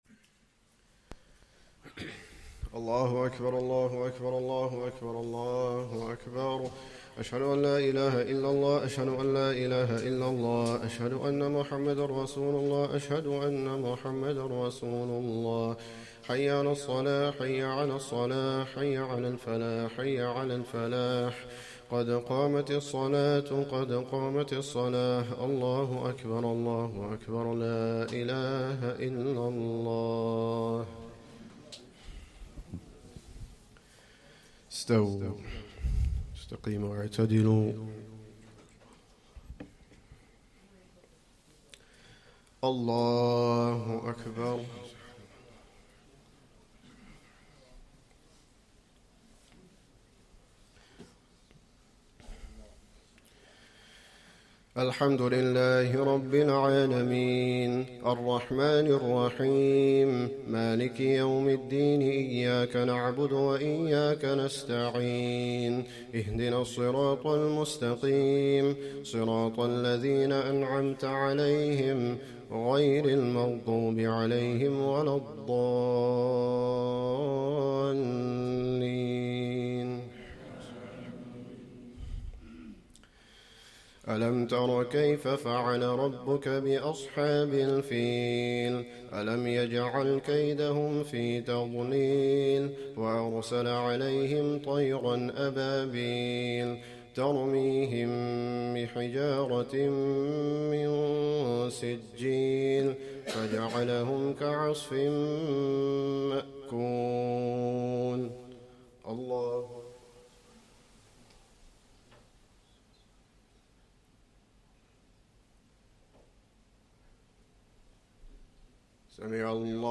Esha + 1st Tarawih prayer - 21st Ramadan 2024